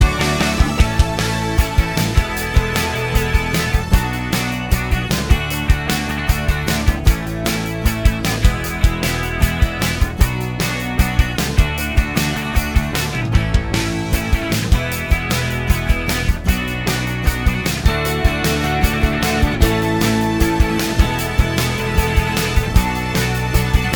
Minus All Guitars Indie / Alternative 3:25 Buy £1.50